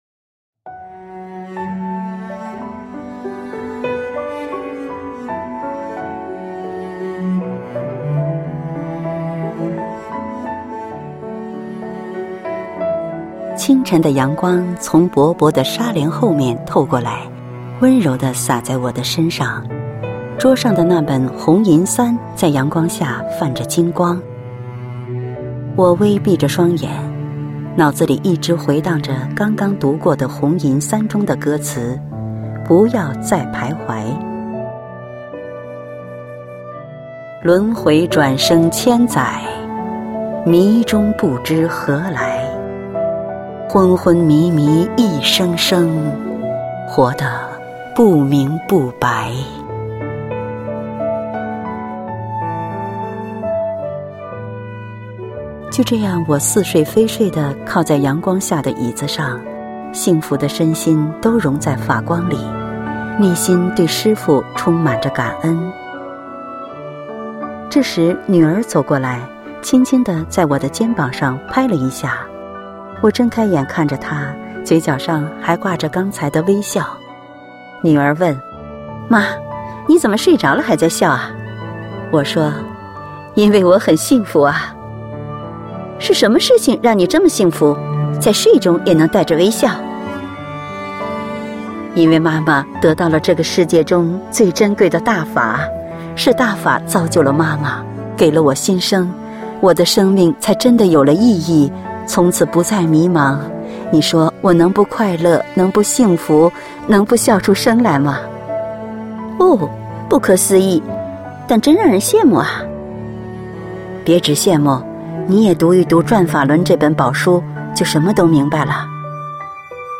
配樂散文朗誦（音頻）：我幸福 因為我有師父